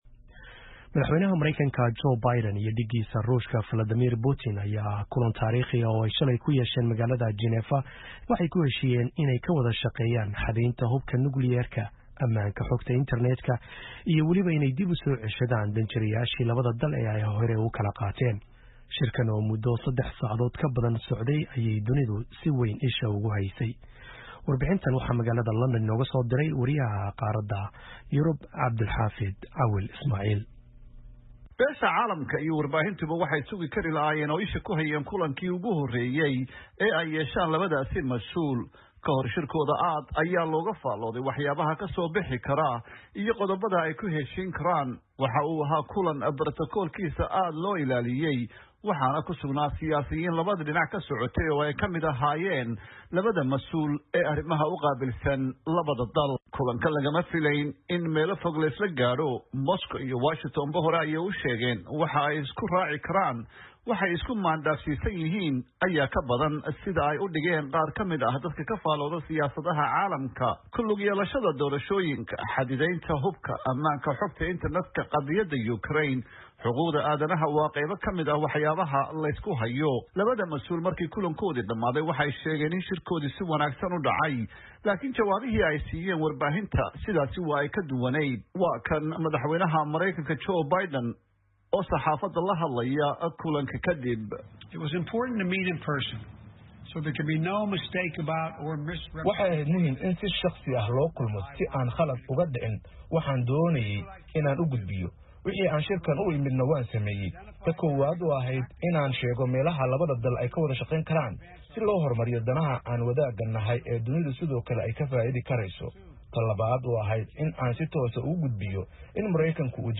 LONDON —